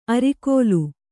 ♪ arikōlu